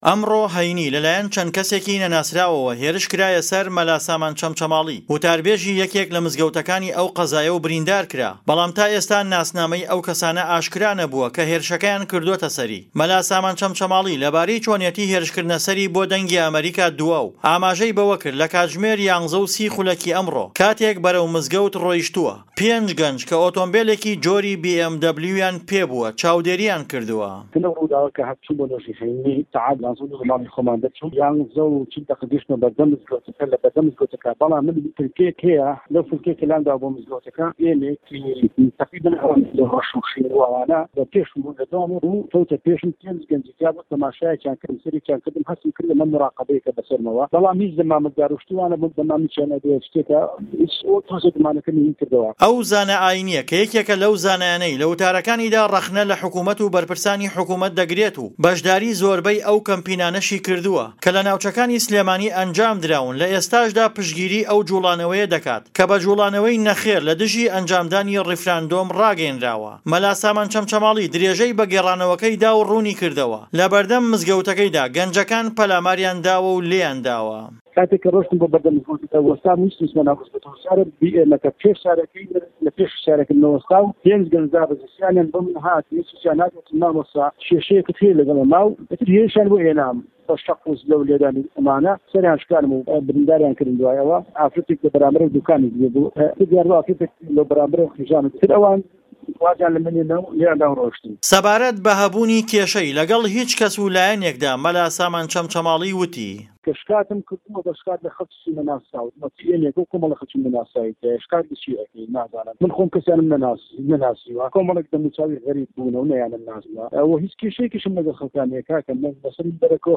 ڕاپۆرت - هێرشی داعش بۆ سه‌ر خێزانێکی عه‌ڕه‌ب له‌ دووبز